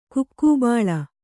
♪ kukkūbāḷa